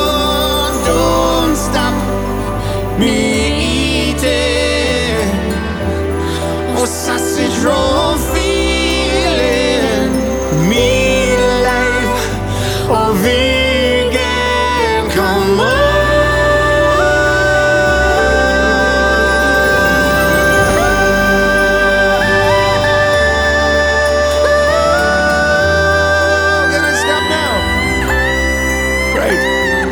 • R&B/Soul